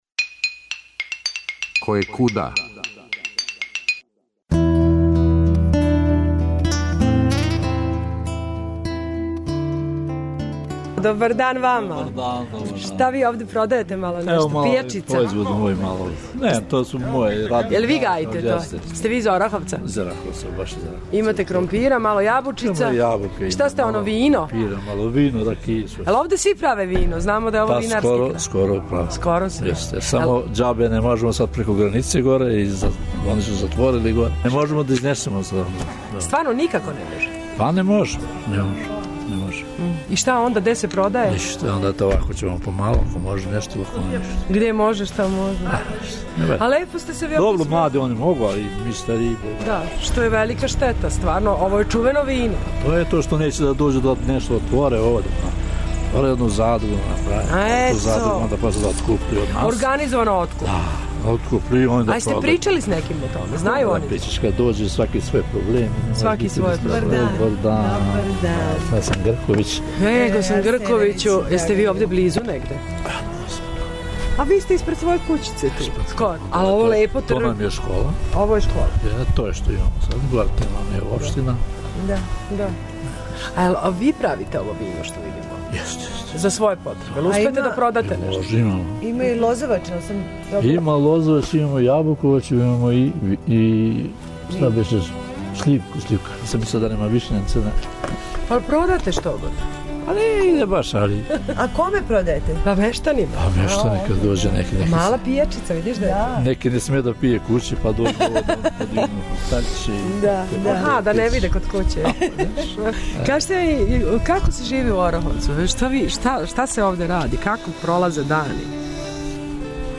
Екипа емисије Којекуда посетила је Србе у Ораховцу прошле године. На малом тргу затекле смо неколико тезги са домаћим вином, воћем и поврћем, и групице мештана који су се дружили испред својих кућа.